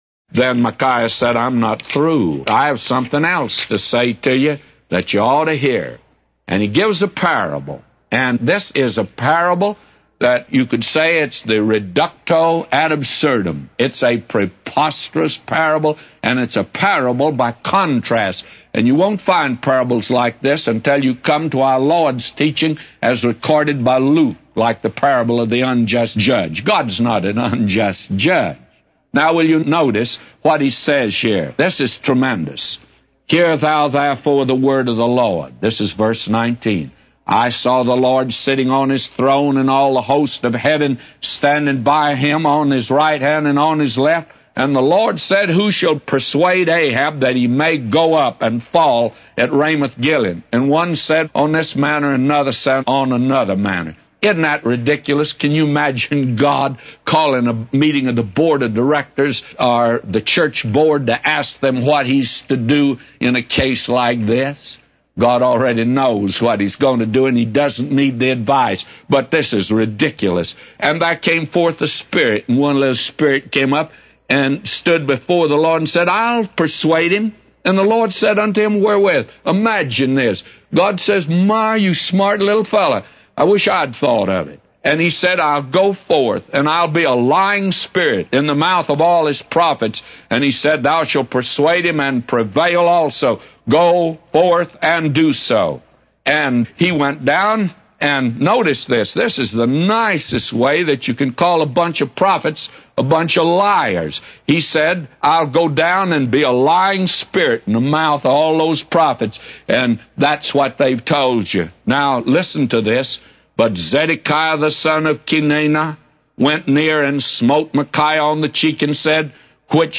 A Commentary By J Vernon MCgee For 1 Kings 22:19-999